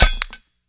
grenade_hit_metal_hvy_02.WAV